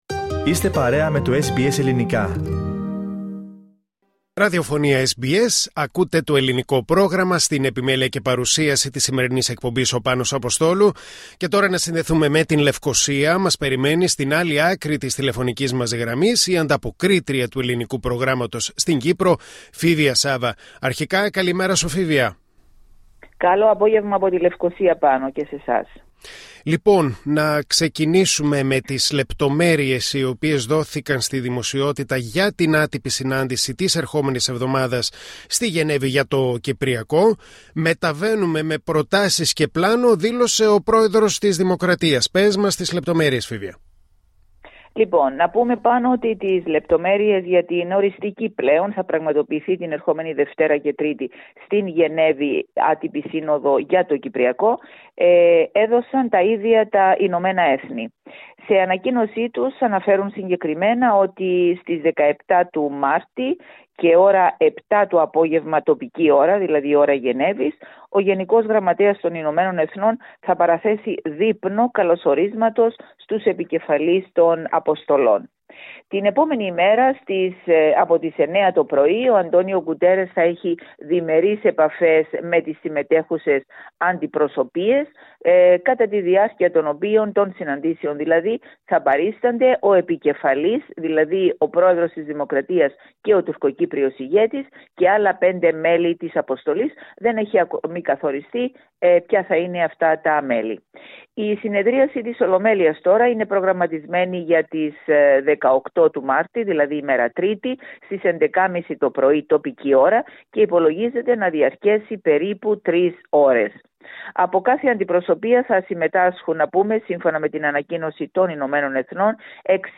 Ακούστε τα υπόλοιπα θέματα της ανταπόκρισης από την Κύπρο, πατώντας PLAY δίπλα από την κεντρική φωτογραφία.